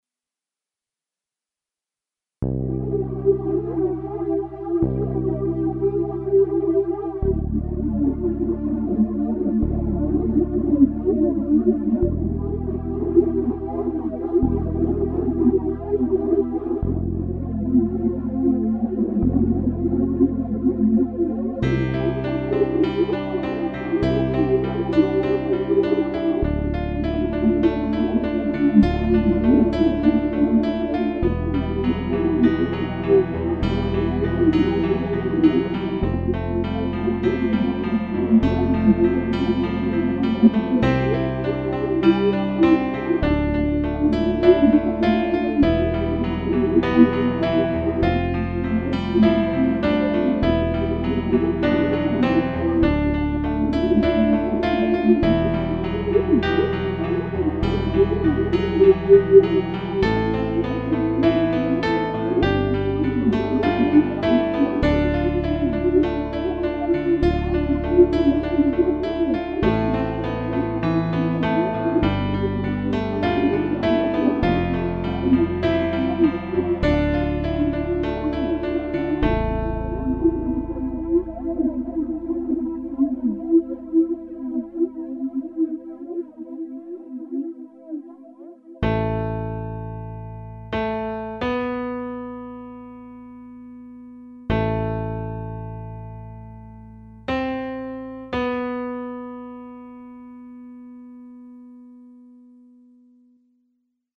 BALLADS